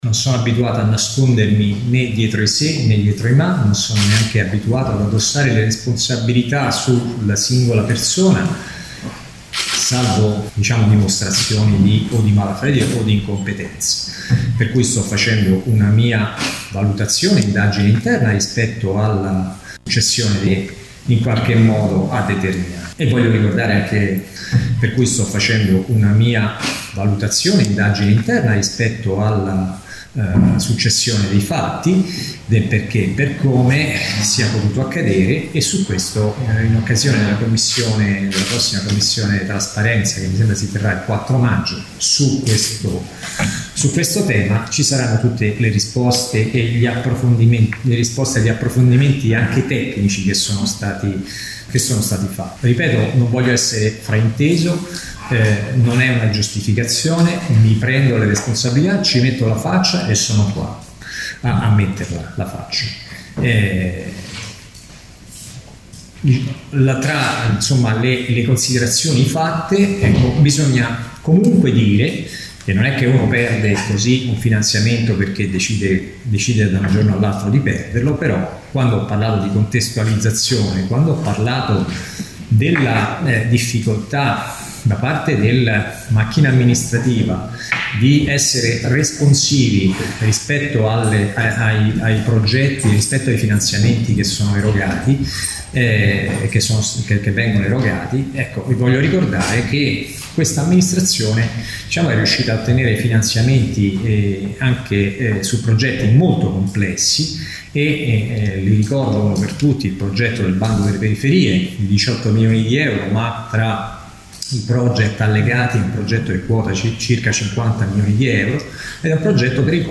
Apre così la conferenza stampa, il sindaco di Latina Damiano Coletta IL VIDEO che risponde alle accuse dell’opposizione sui 4,5 milioni di euro del piano triennale delle opere pubbliche che il Comune dovrà restituire per non essere stato in grado di utilizzarli.